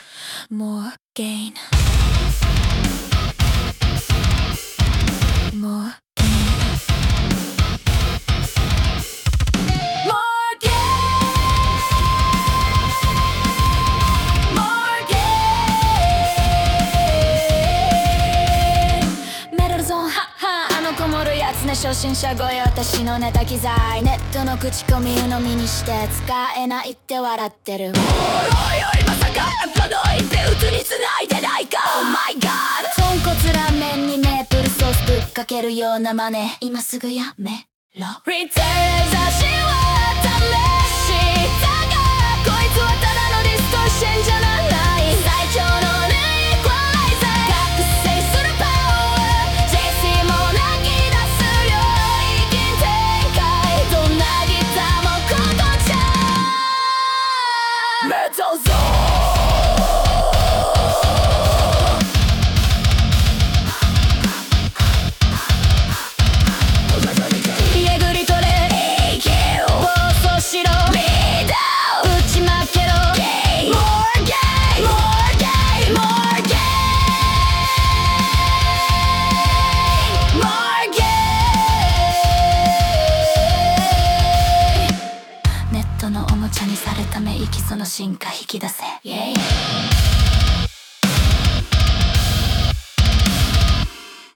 【BOSS MT-2 】メタルゾーンは積極的な音作りができる激歪みイコライザー！